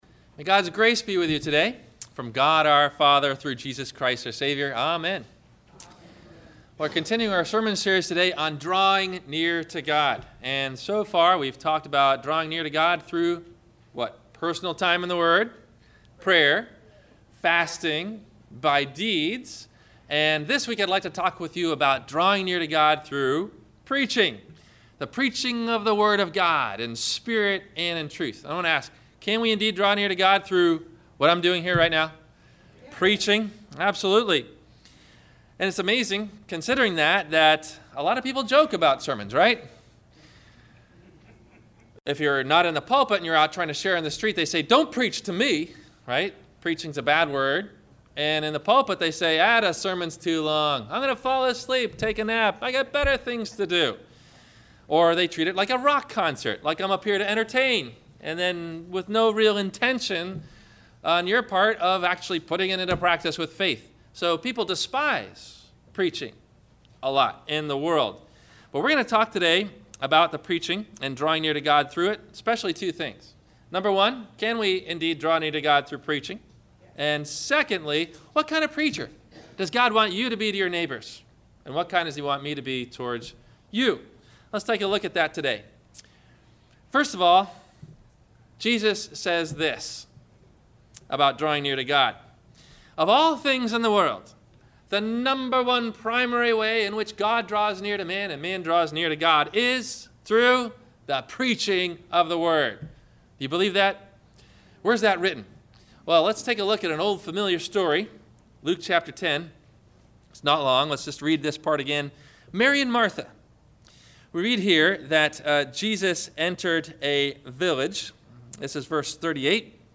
What’s The Job of a True Preacher of God? – Sermon – October 05 2014